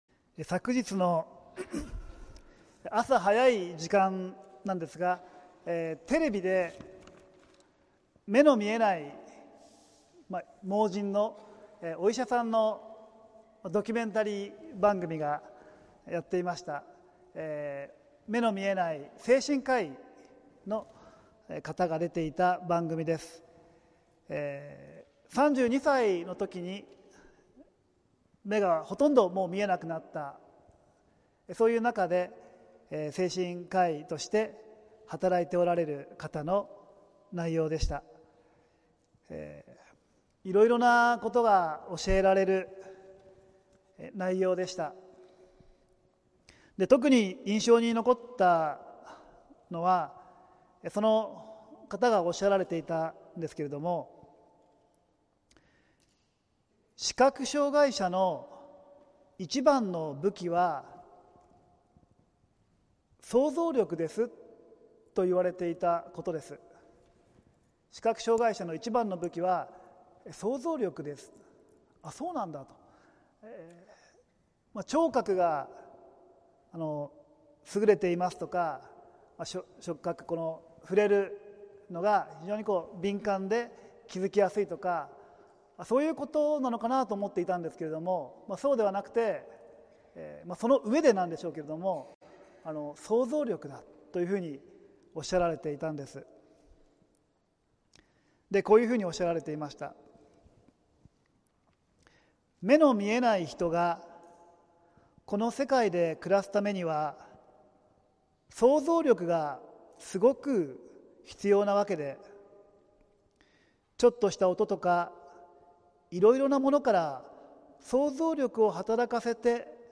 浦和福音自由教会(さいたま市浦和区)の聖日礼拝(2024年5月12日)「救いに至る信仰｣(週報とライブ/動画/音声配信)